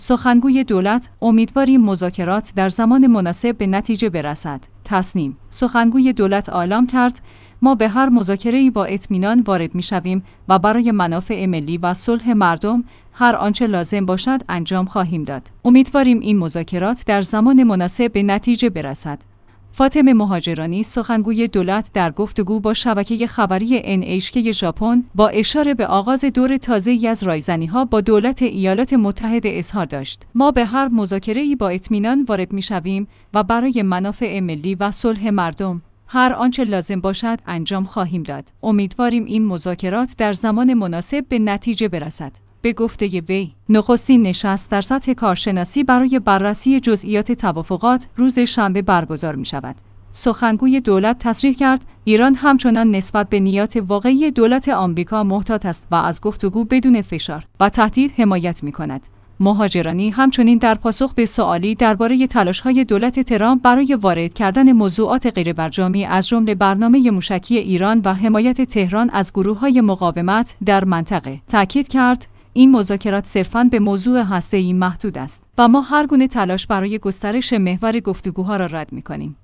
امیدواریم این مذاکرات در زمان مناسب به نتیجه برسد. فاطمه مهاجرانی سخنگوی دولت در گفت‌وگو با شبکه خبری NHK ژاپن، با اشاره به آغاز دور تازه‌ای از رایزنی‌ها با دولت